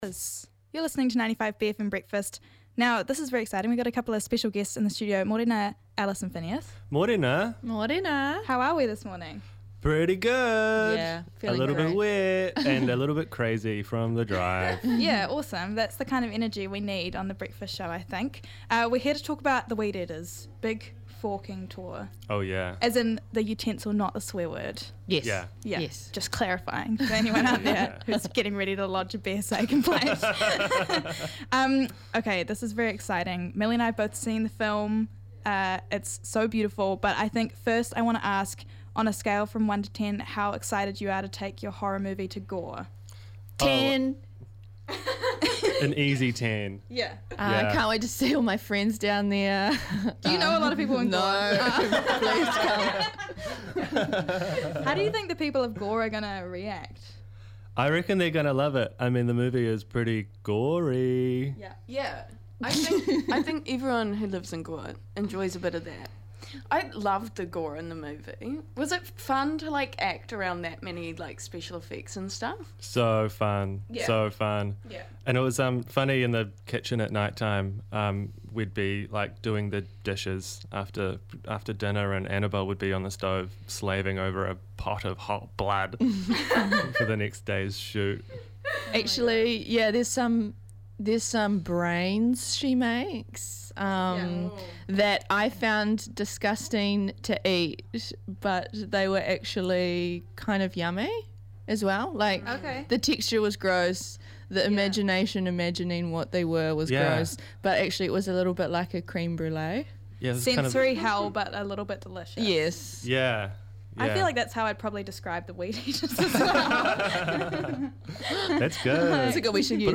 Guest Interview w/ The Weed Eaters: Rāpare March 26, 2026